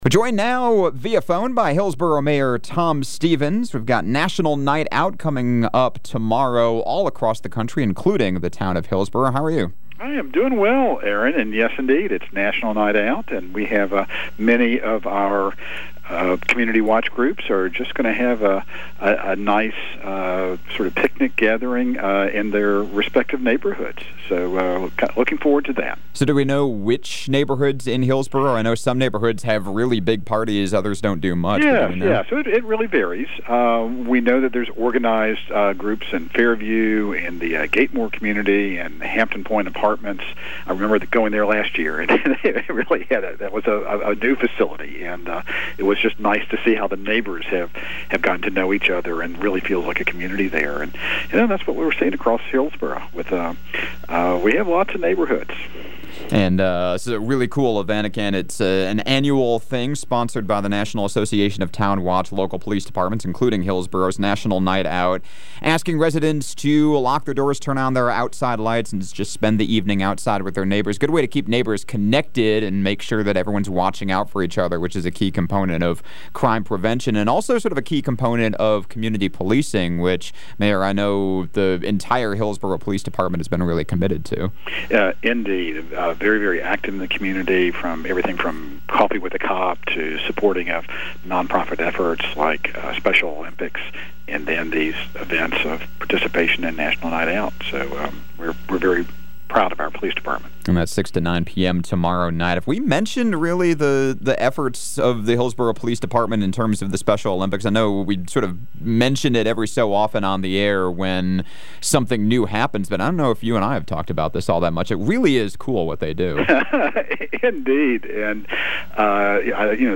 Conversations with the Mayors